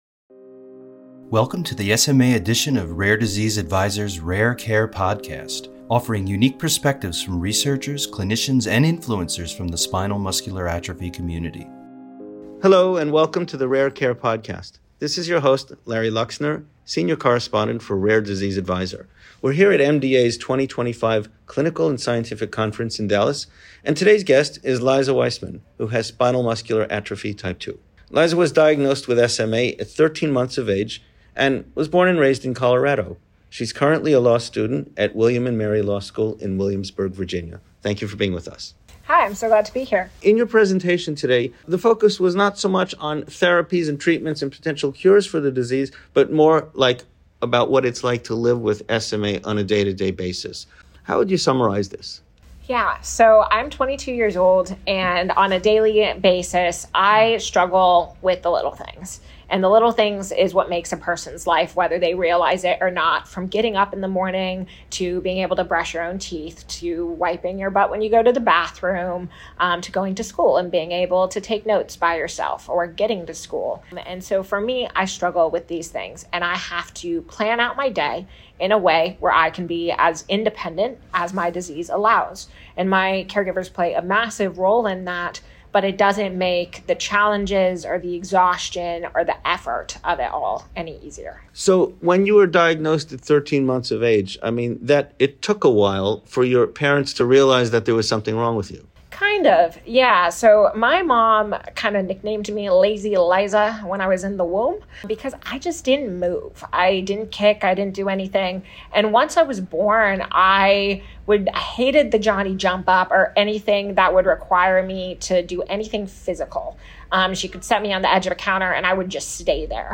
An Interview